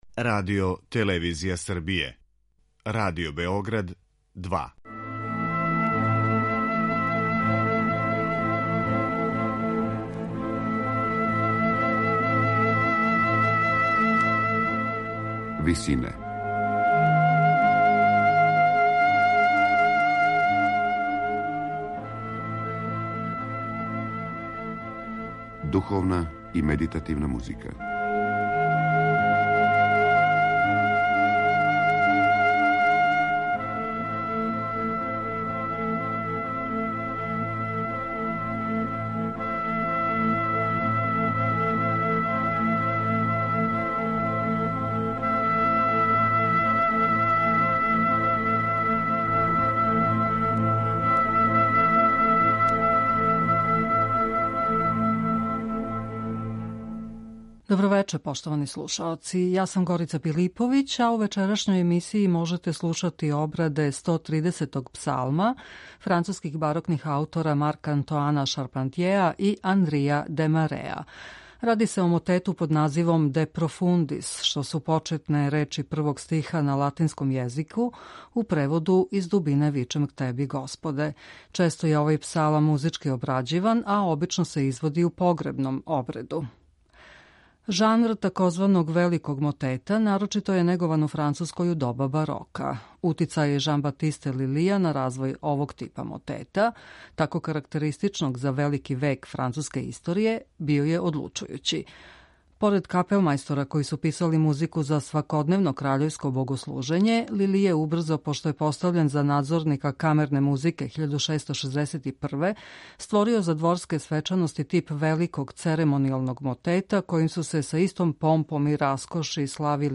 Ради се о мотету под називом De profundis . Жанр тзв. великог мотета нарочито је негован у Француској у доба барока.